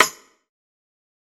Snares
TC2 Snare 4.wav